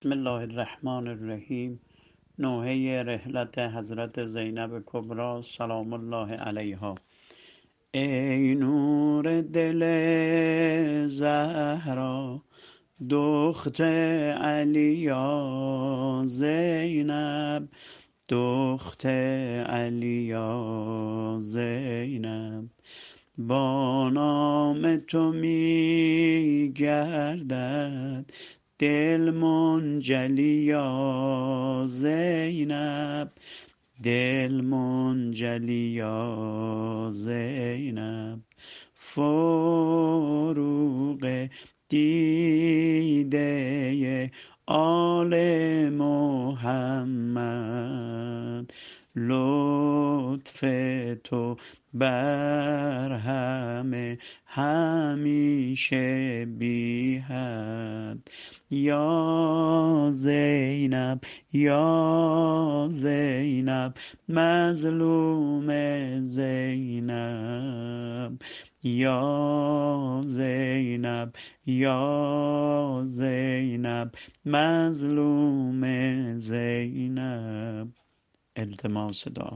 متن شعر و نوحه رحلت حضرت زینب سلام الله علیها -(ای نور دل زهرا(دخت علی یا زینب)۲)